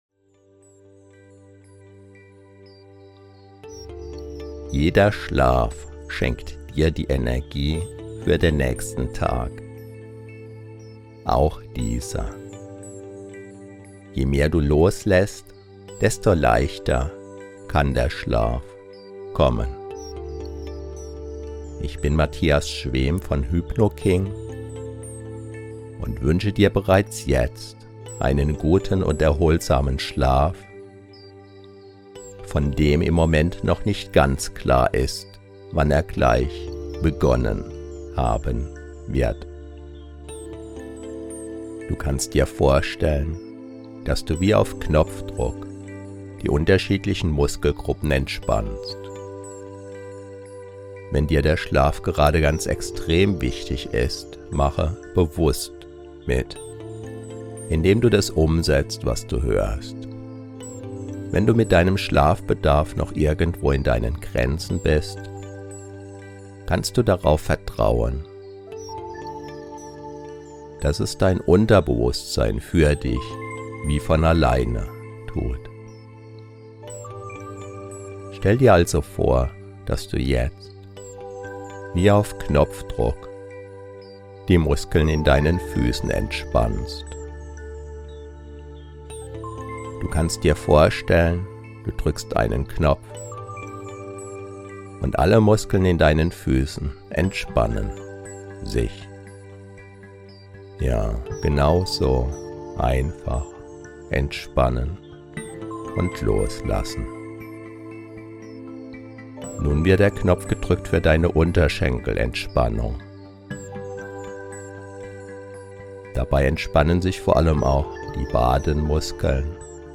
Diese Einschlaf-Hypnose begleitet Dich sanft in den Schlaf und lädt Deine Energiequellen wieder auf.